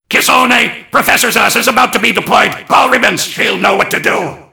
mvm_bomb_alerts12.mp3